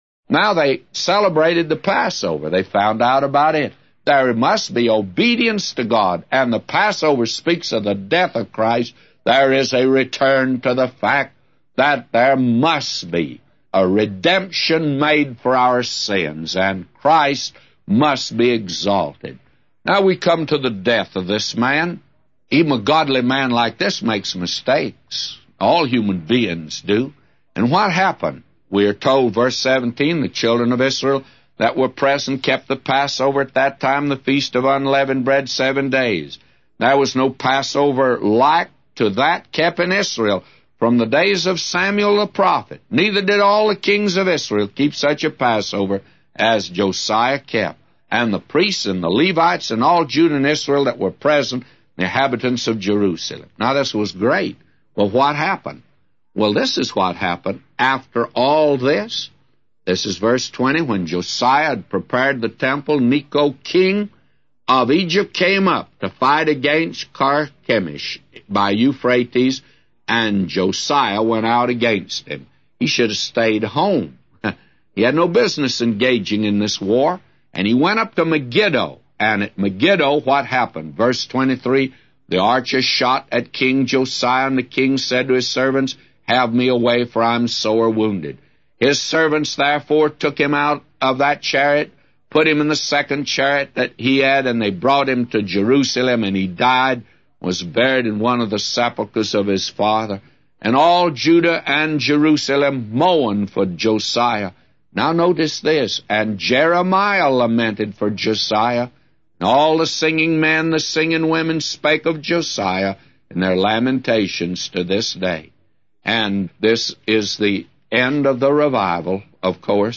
A Commentary By J Vernon MCgee For 2 Chronicles 35:1-999